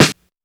just blaze d.r..c.snare.WAV